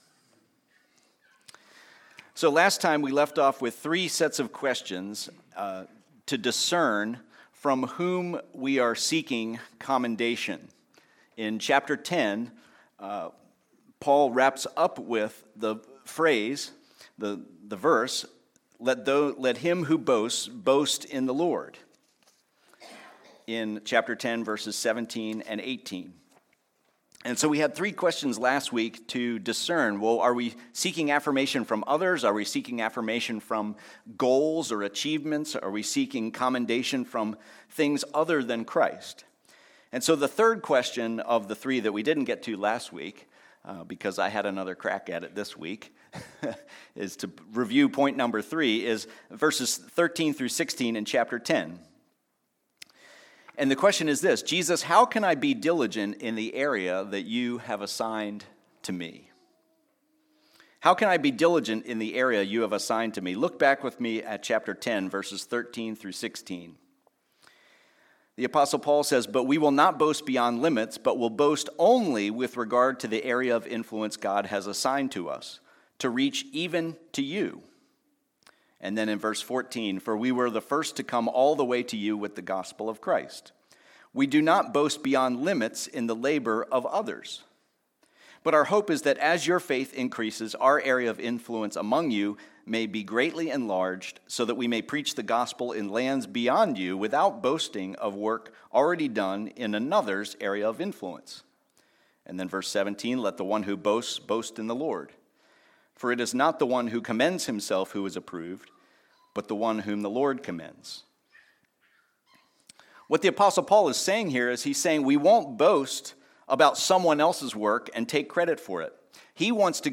Deception is Disguised - Trinity Community Church